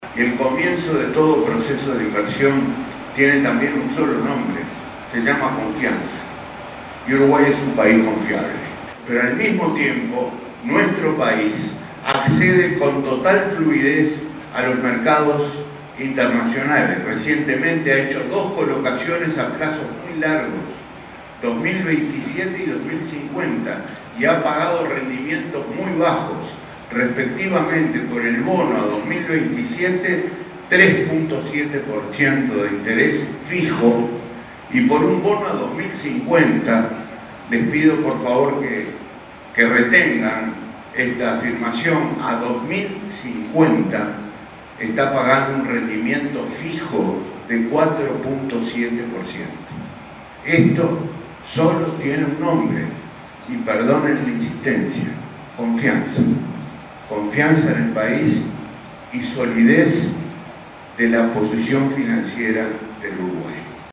Al exponer ante el Consejo de China para la Promoción del Comercio Internacional, Astori afirmó: “En los últimos años, los uruguayos hemos aprendido que hay un solo camino para consolidar el crecimiento y se identifica con una sola palabra: inversión”.